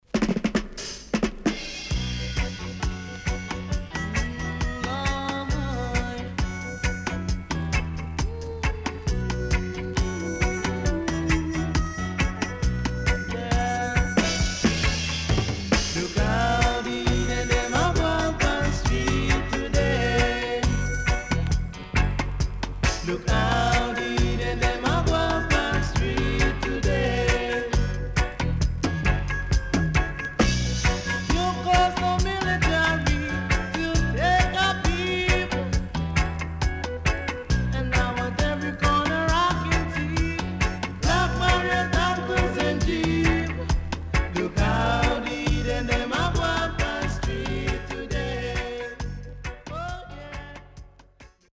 SIDE A:少しノイズ入りますが良好です。
SIDE B:少しノイズ入りますが良好です。